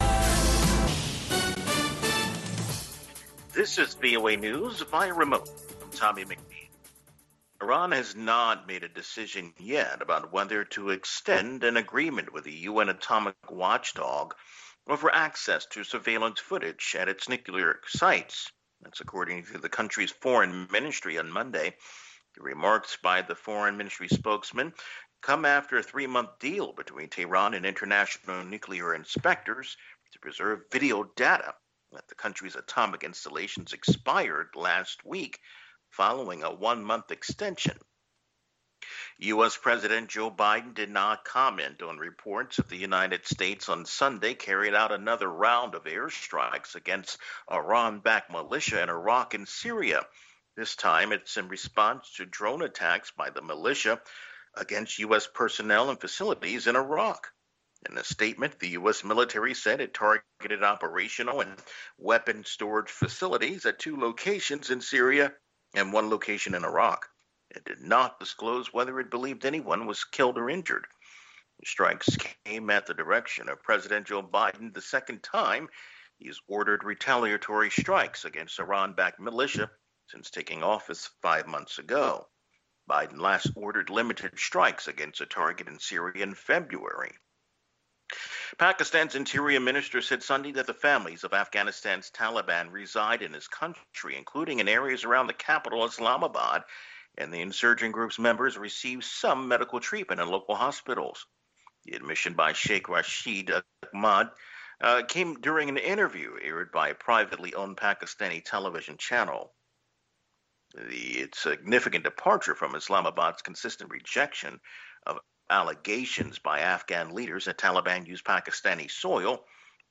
African Beat showcases the latest and the greatest of contemporary African music and conversation.